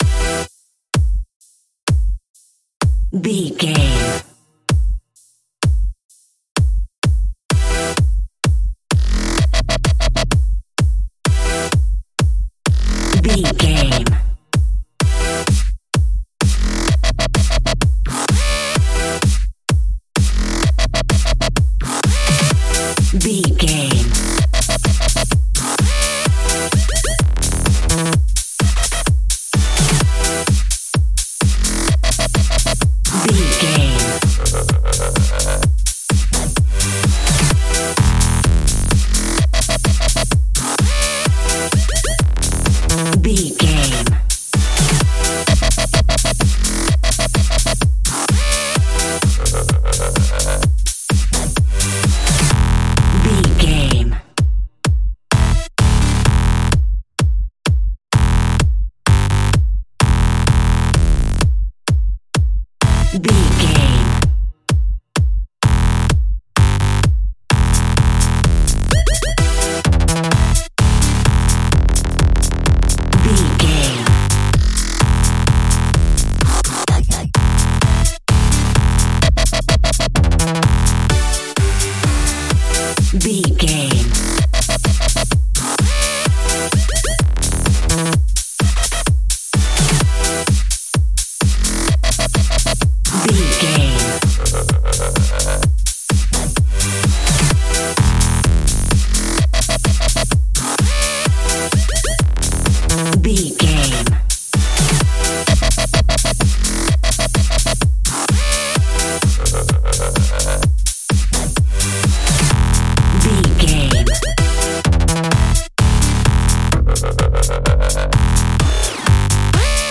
Chart Top 40 Dubstep.
Aeolian/Minor
aggressive
powerful
dark
funky
uplifting
futuristic
driving
energetic
synthesiser
drums
drum machine
breakbeat
instrumentals
synth leads
synth bass